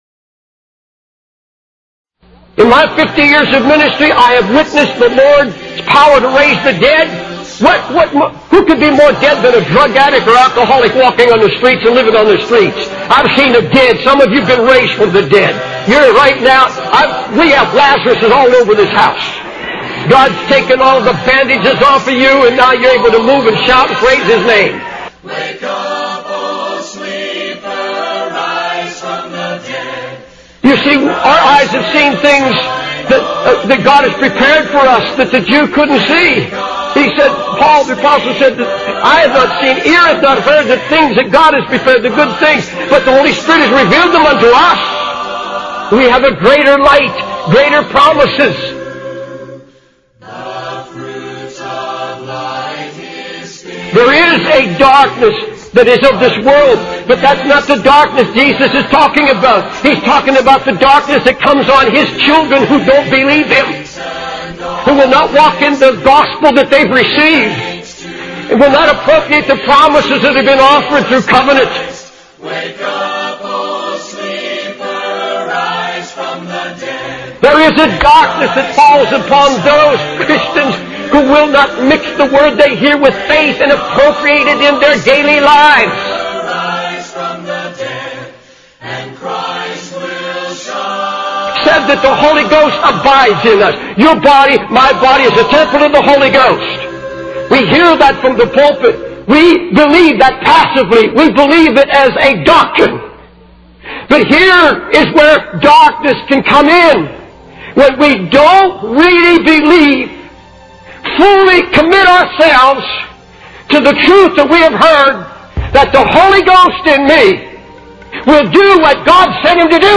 In this sermon, the preacher emphasizes the importance of walking in the light and not focusing on one's weaknesses and abilities. He refers to the story of the 10 spies who gave an evil report about the land of milk and honey, highlighting their lack of faith and belief in their own abilities.